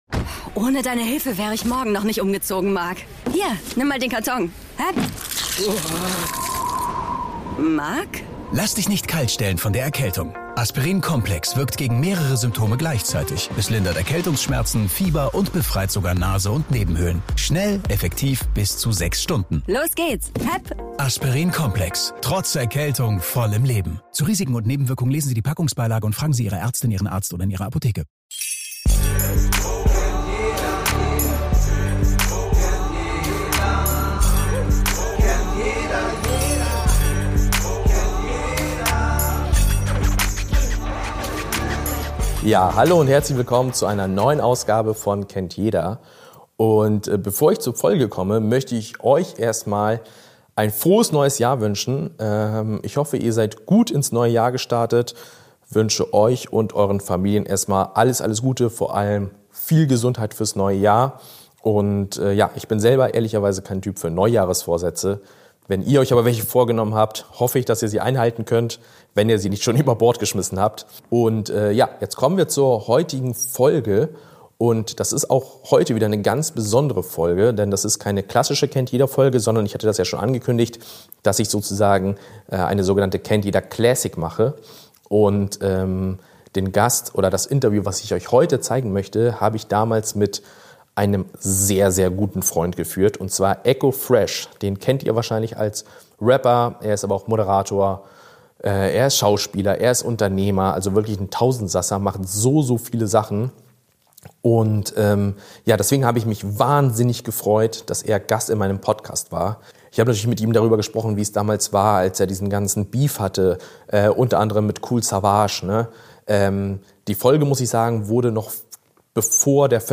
Das merkt man sofort: Das Gespräch ist vertraut, ehrlich und voller persönlicher Einblicke. Eko spricht offen darüber, wie ihn der legendäre Beef mit Kool Savas über Jahre begleitet hat und welchen Einfluss dieser Konflikt auf seine Karriere, sein Selbstbild und sein Leben hatte.